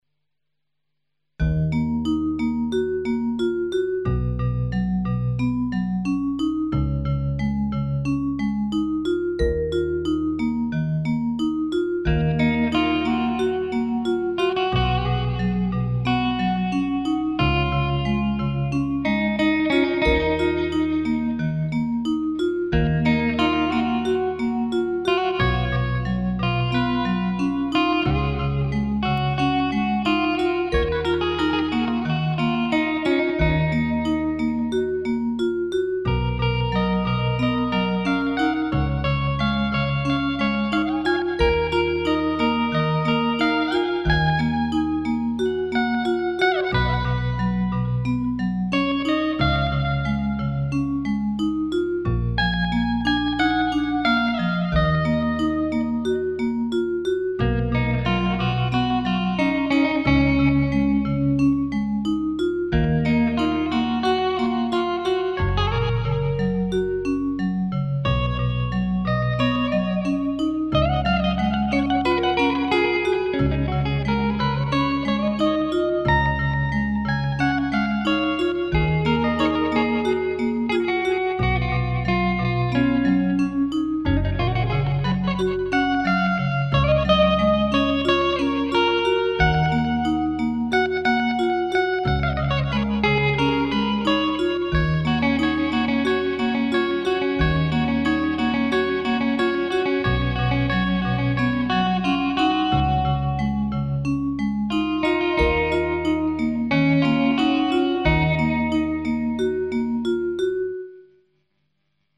没接触过吉他的我就不评论好坏了，不过听着的确很舒服，但是可是最后突然就停了呢，结尾这块。。真好听，还有没有啊，再发几段啊。